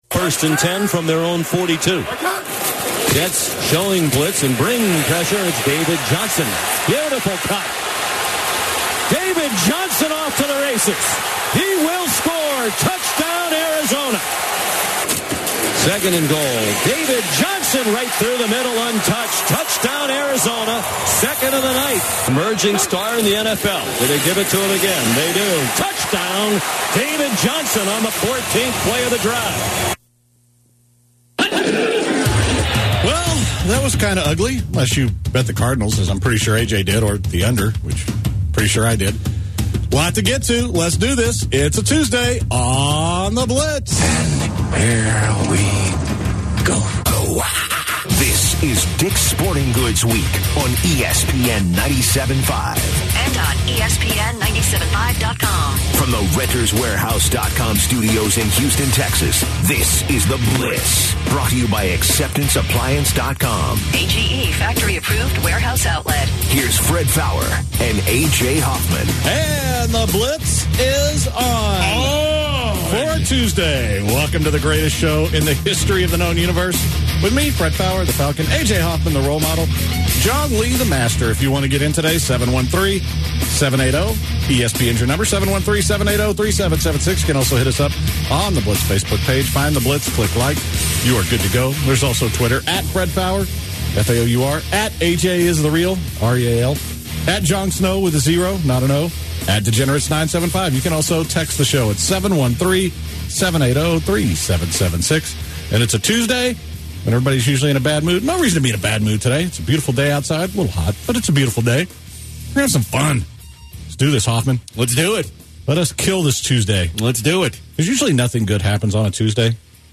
They go on to talk about Halloween and each states favorite candy. They guys talk a caller who ask the guys what they thought about the Cowboys and the way they are being coached.